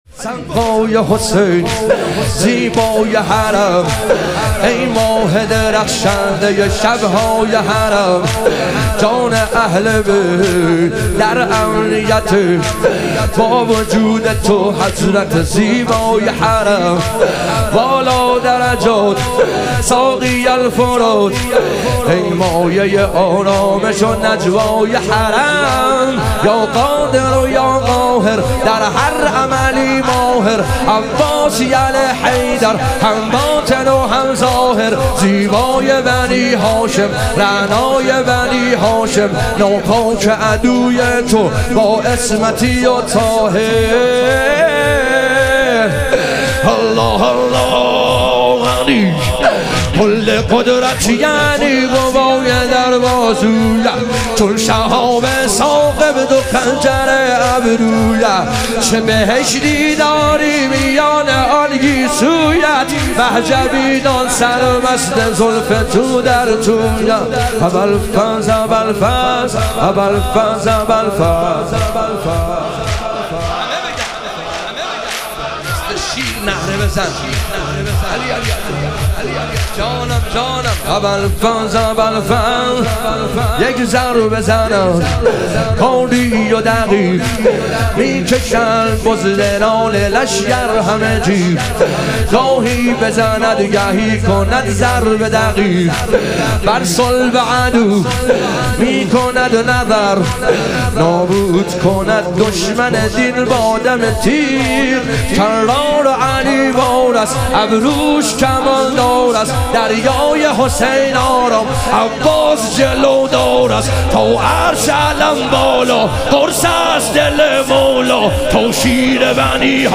شهادت حضرت زینب کبری علیها سلام - تک